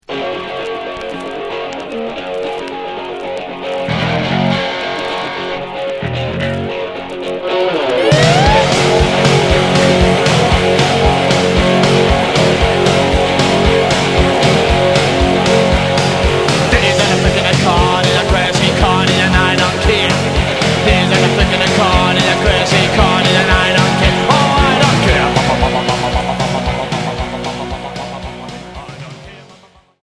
Psychobilly Unique 45t retour à l'accueil